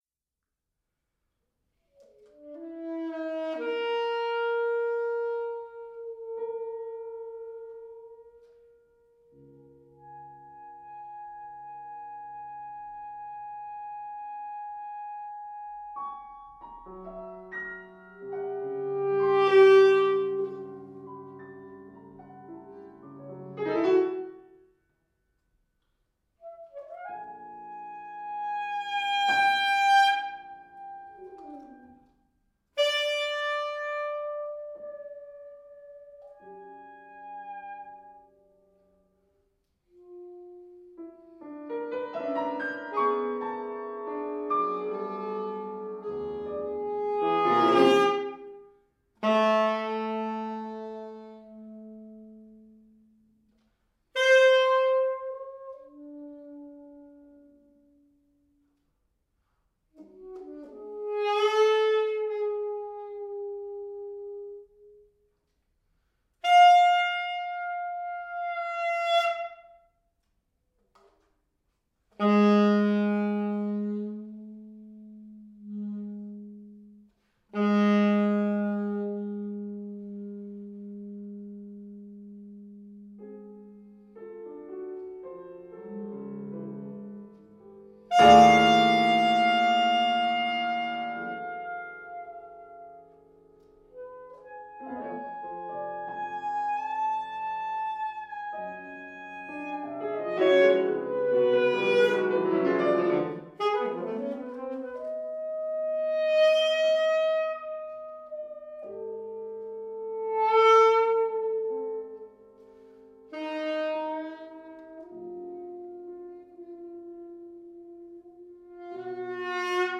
alto saxophone
piano